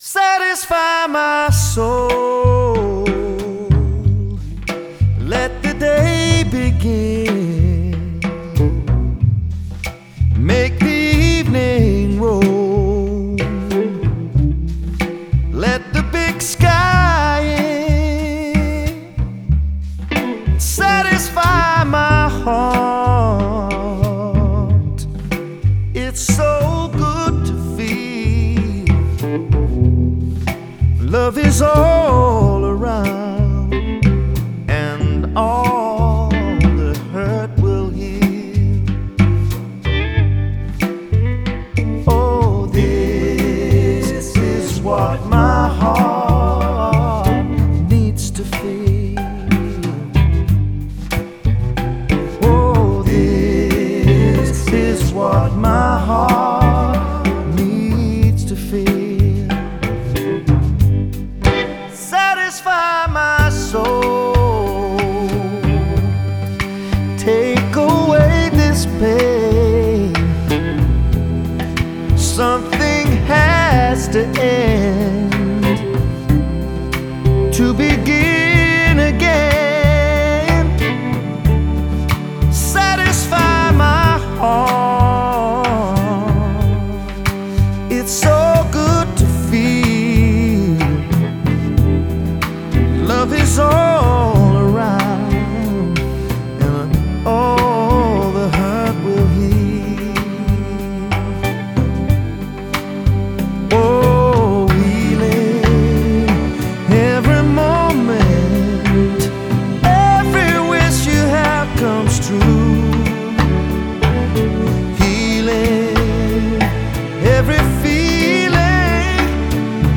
Genre: Pop/Rock, Blue-eyed Soul